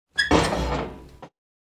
open-door.wav